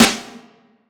Boom-Bap Snare 59.wav